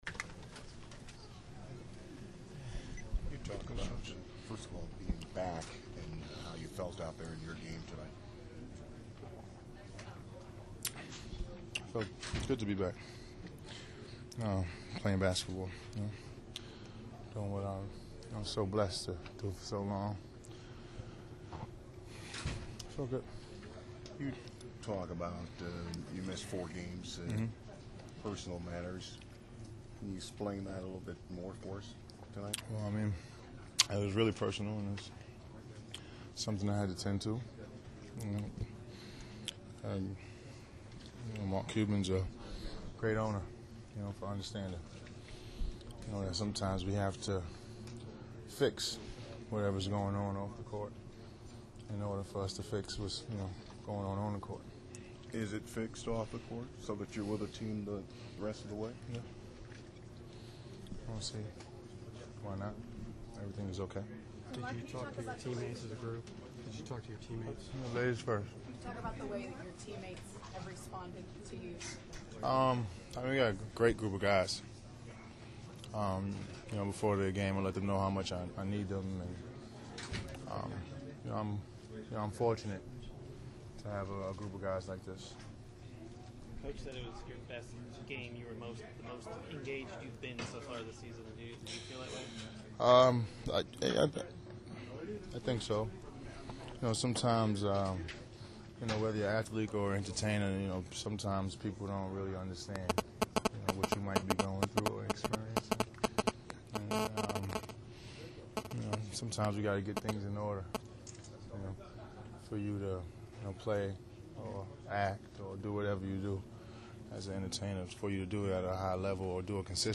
LISTEN: Lamar Odom post game interview (iPad/iPhone - click here)